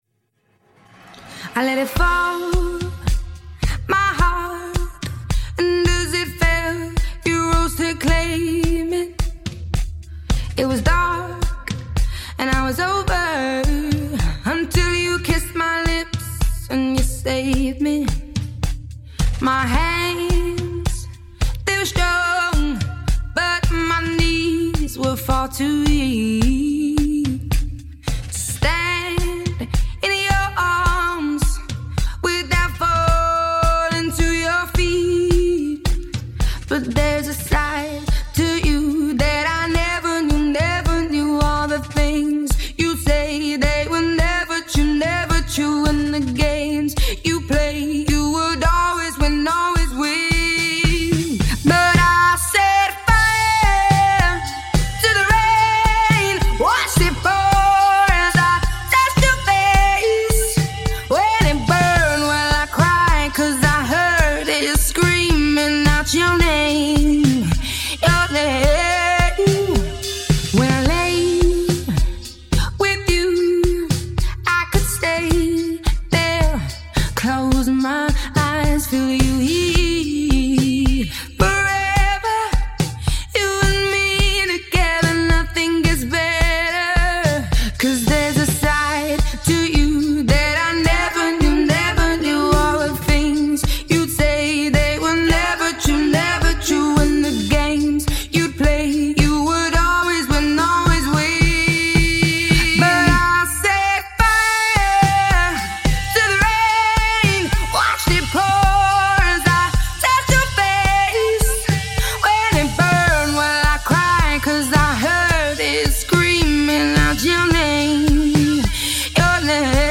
أغانيك المفضلة بدون المعازف الموسيقية